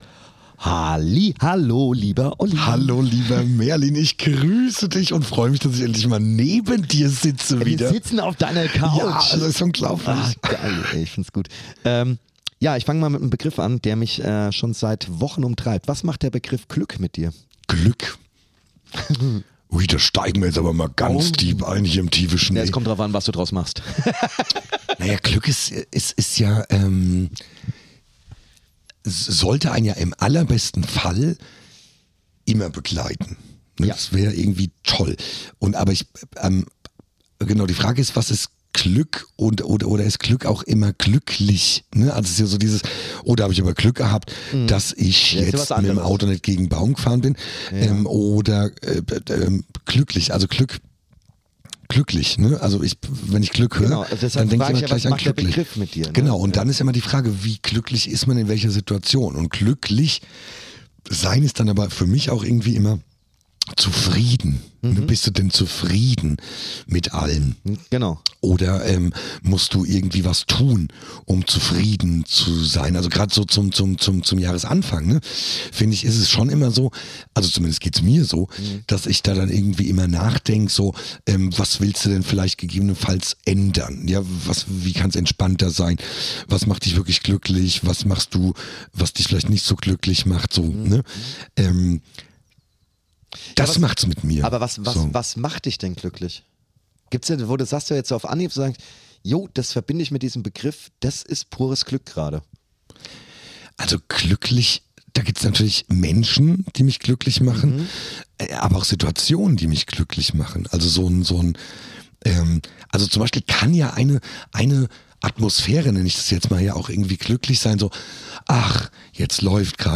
Tiefgründig, laut, ehrlich, witzig – und wie immer völlig ungeschnitten.